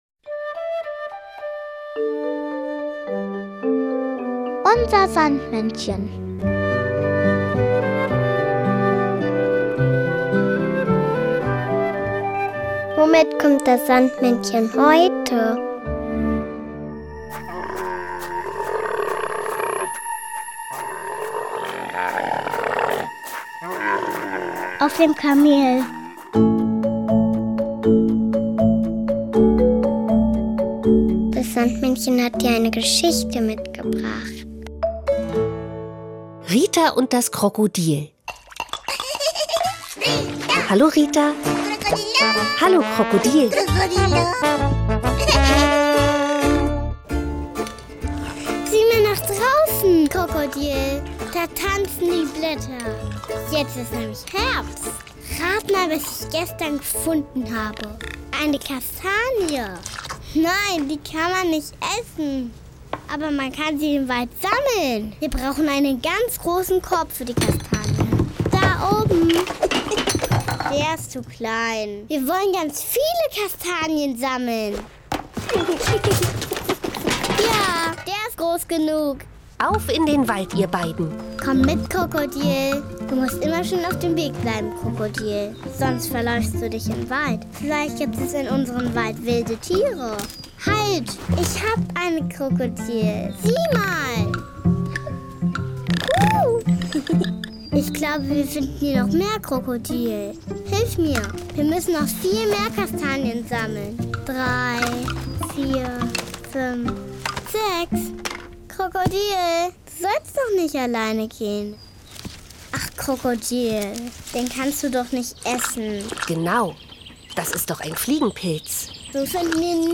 Geschichte mitgebracht, sondern auch noch das Kinderlied